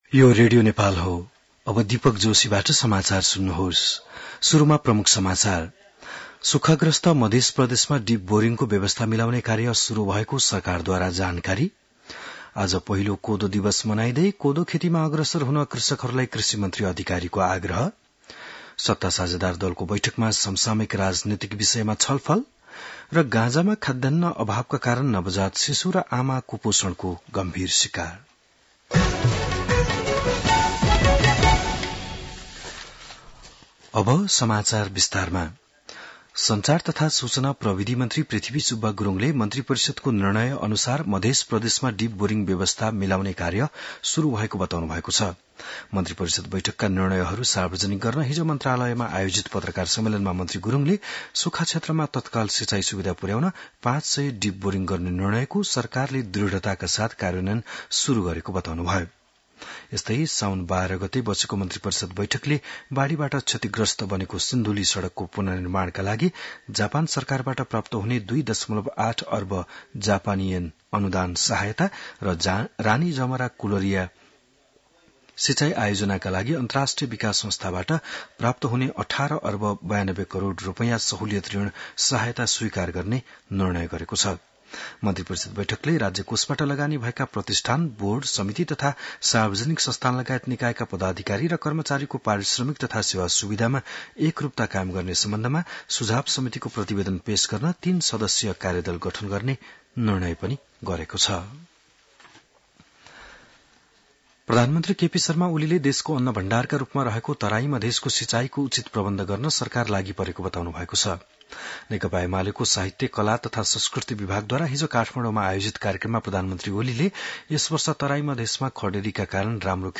बिहान ९ बजेको नेपाली समाचार : १६ साउन , २०८२